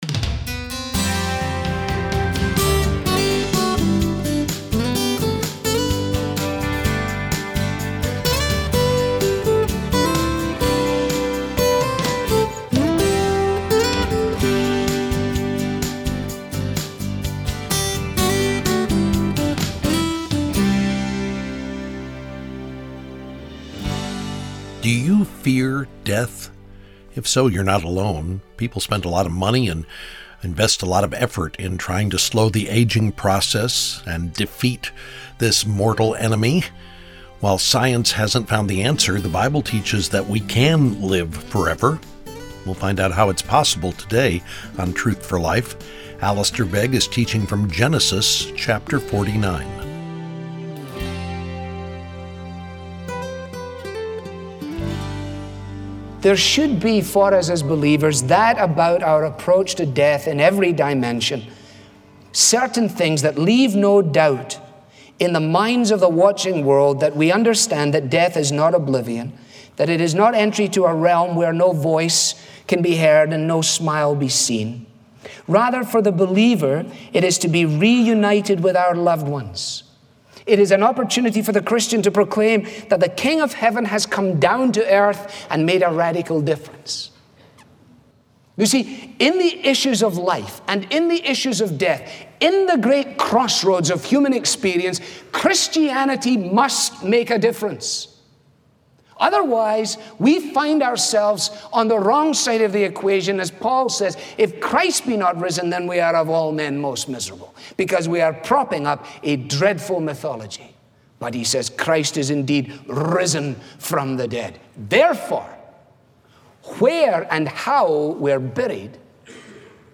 This listener-funded program features the clear, relevant Bible teaching